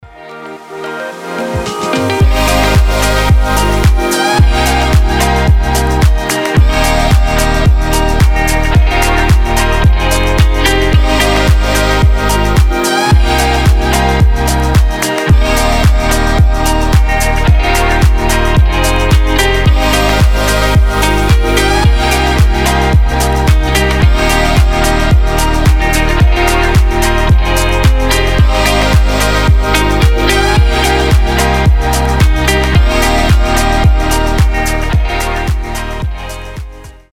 • Качество: 320, Stereo
красивые
мелодичные
Electronic
без слов
chillout
расслабляющие
Вдохновляющая утренняя мелодия